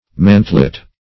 Mantlet \Man"tlet\, n.